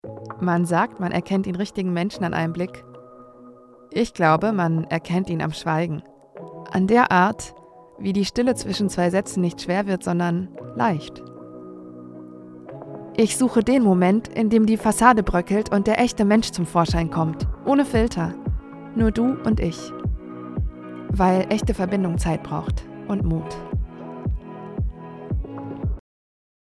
markant
Mittel minus (25-45)
Russian, Eastern European
Comment (Kommentar), Commercial (Werbung)